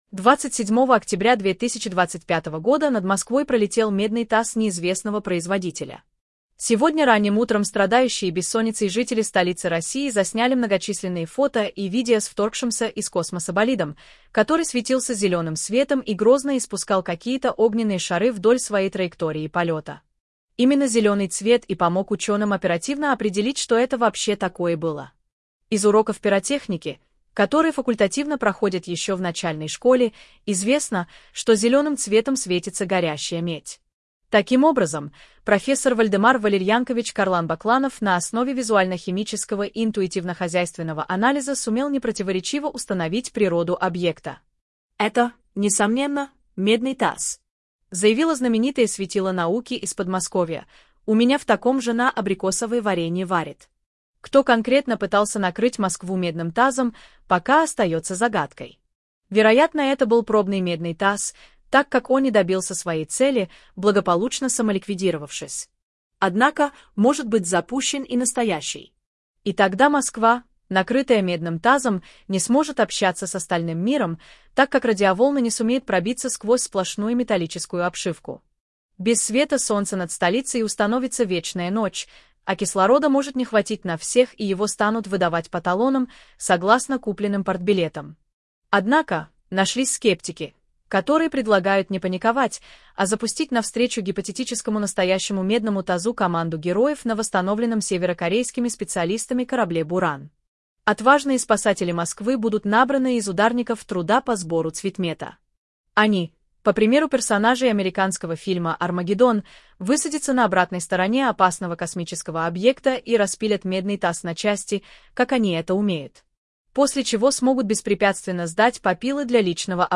Голос «Дина», синтез речи нейросетью